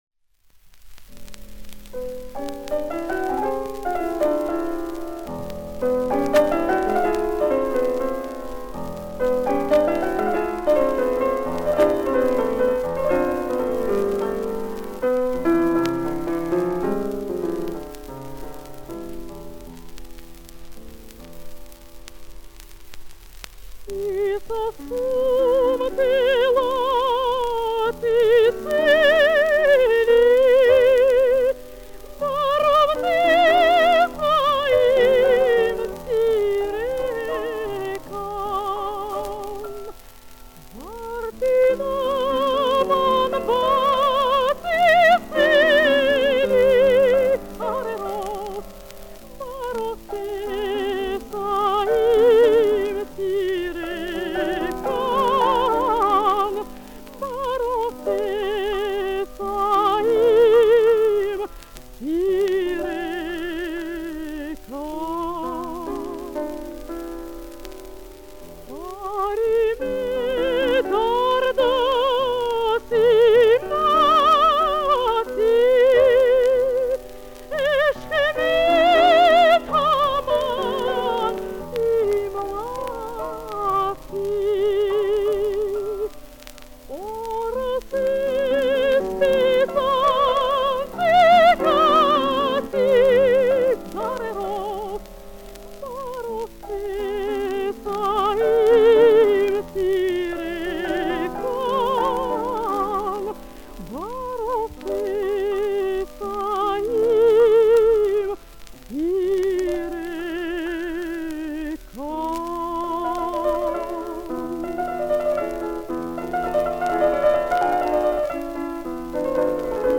ф-но
Источник грампластинка